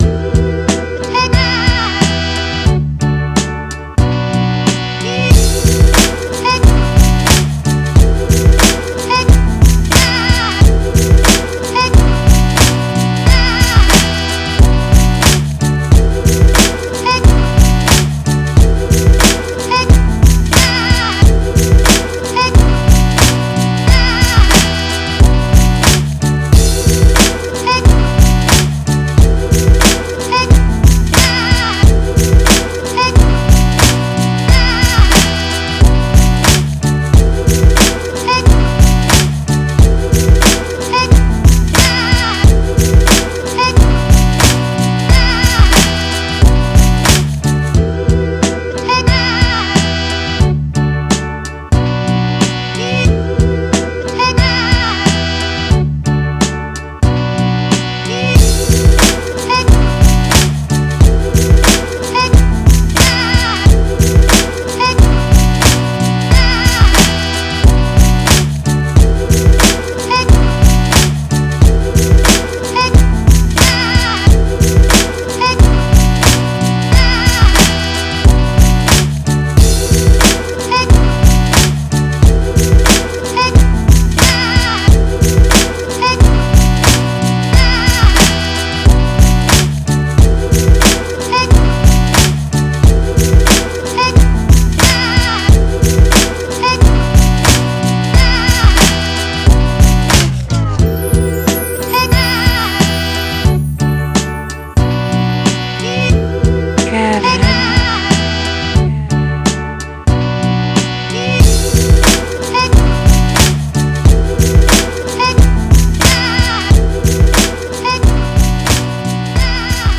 instrumental album